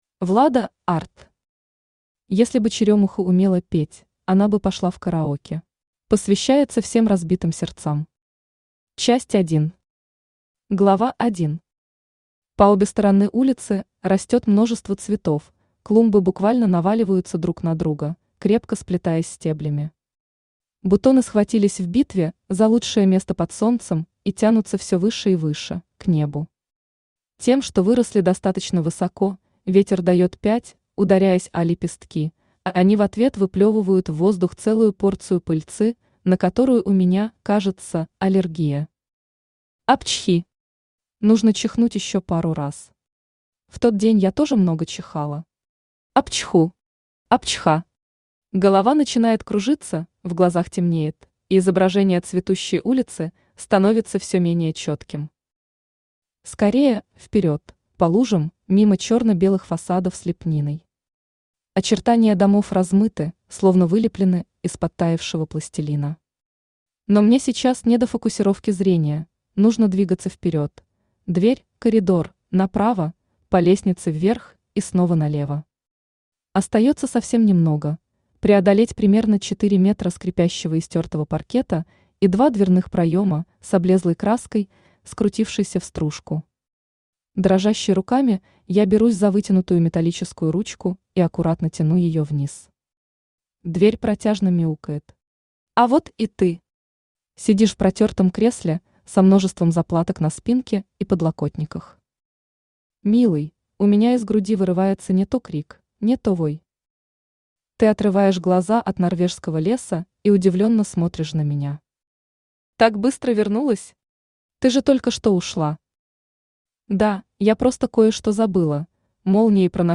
Аудиокнига Если бы черёмуха умела петь, она бы пошла в караоке | Библиотека аудиокниг
Aудиокнига Если бы черёмуха умела петь, она бы пошла в караоке Автор Влада Арт Читает аудиокнигу Авточтец ЛитРес.